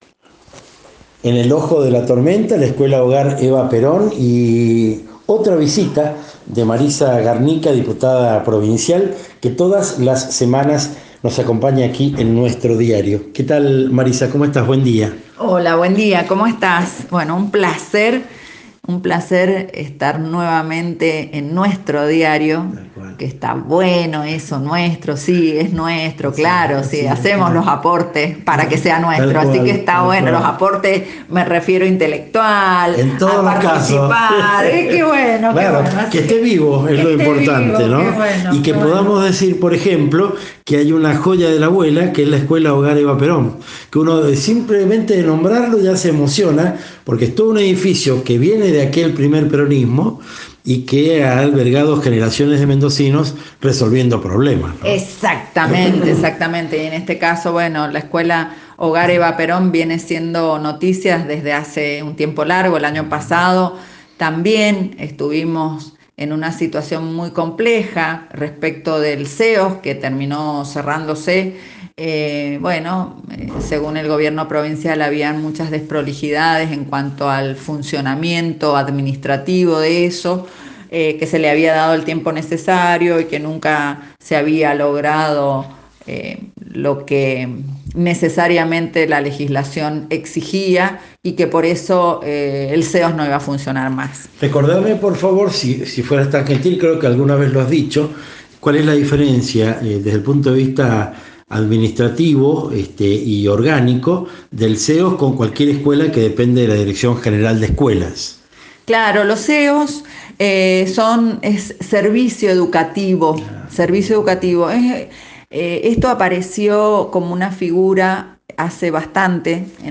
Entrevista: Marisa Garnica, Diputada Provincial, 18 de abril de 2023